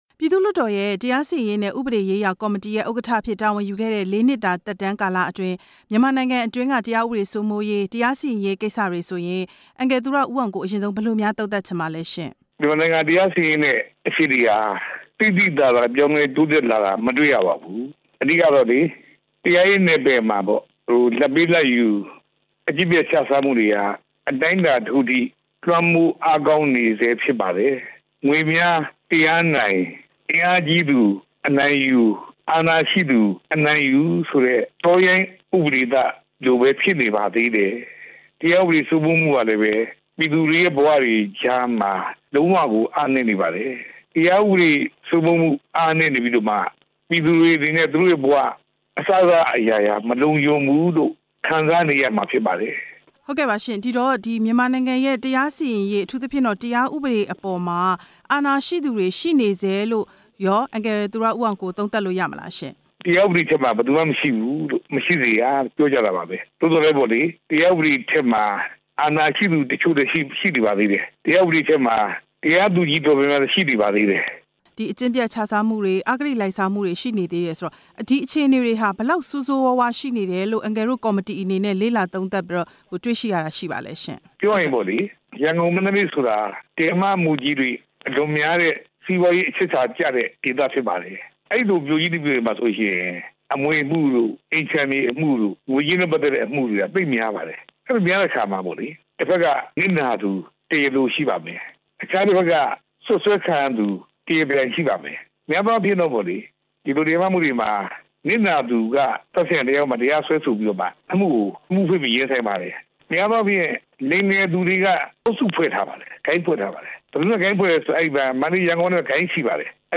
သူရဦးအောင်ကိုနဲ့ မေးမြန်းချက်